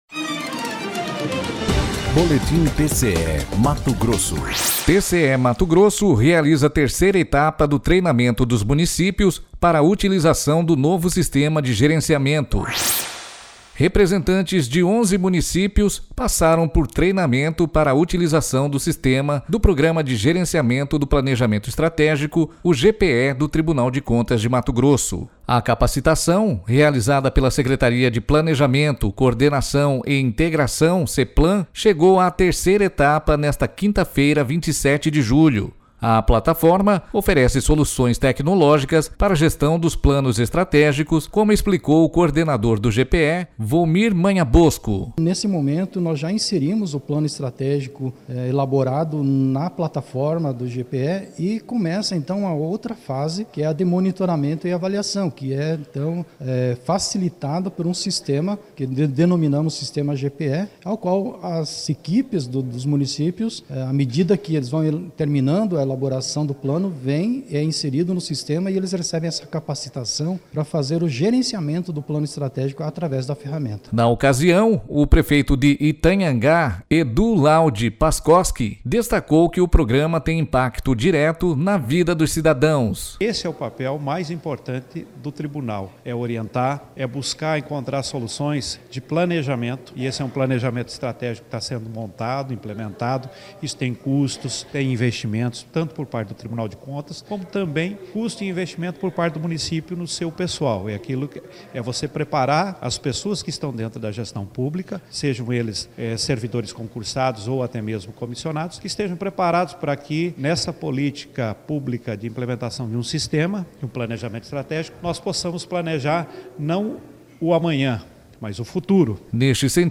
Na ocasião, o prefeito de Itanhangá, Edu Laudi Pascoski, destacou que o Programa tem impacto direto na vida dos cidadãos.//
Sonora: Edu Laudi Pascoski - prefeito de Itanhangá